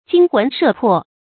驚魂攝魄 注音： ㄐㄧㄥ ㄏㄨㄣˊ ㄕㄜˋ ㄆㄛˋ 讀音讀法： 意思解釋： 見「驚魂奪魄」。